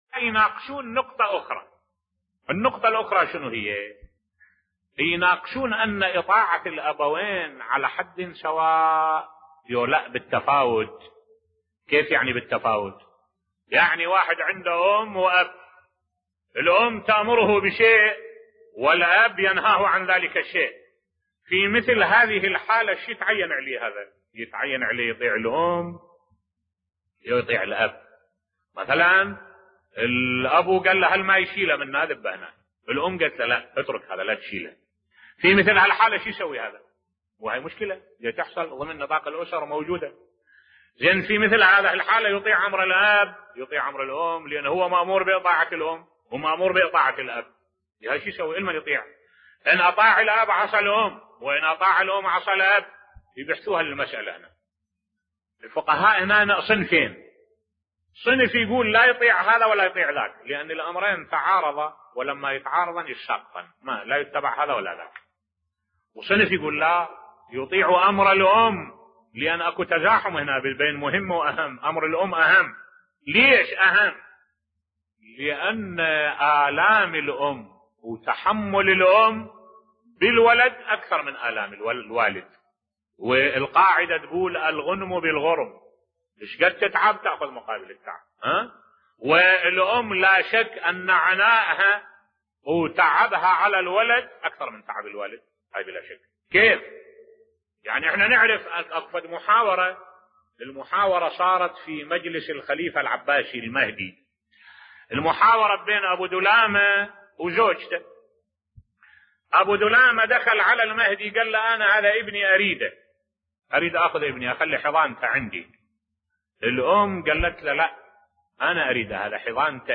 ملف صوتی قصة أبي دلامة و زوجته بصوت الشيخ الدكتور أحمد الوائلي